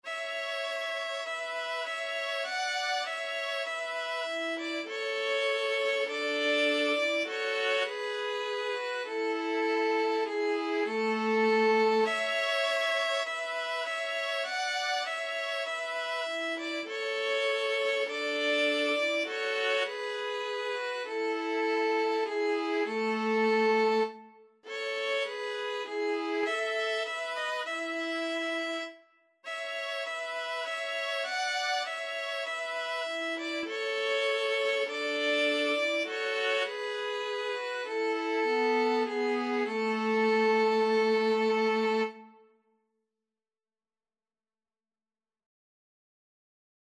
Moderato
4/4 (View more 4/4 Music)
Traditional (View more Traditional Violin-Viola Duet Music)